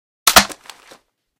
reload_end.ogg